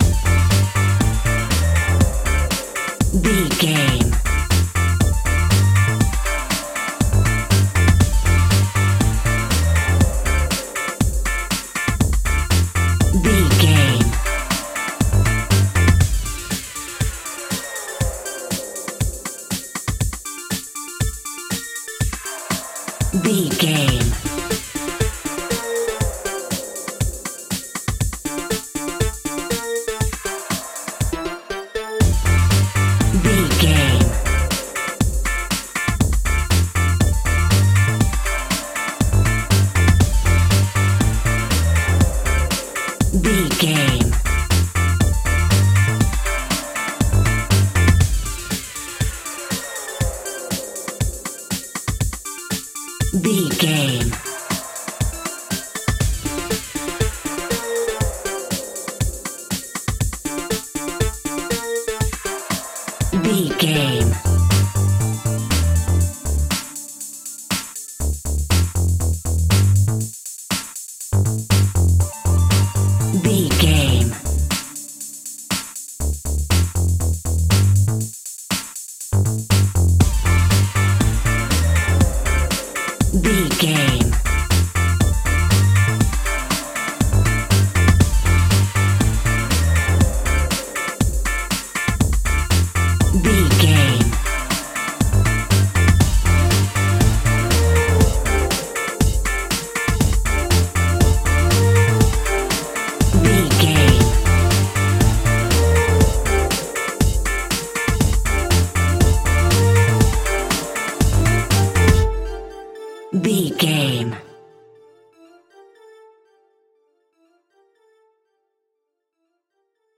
Aeolian/Minor
D
groovy
cheerful/happy
futuristic
uplifting
optimistic
strings
synthesiser
drum machine
electronica
synth leads
synth bass
synth pad
robotic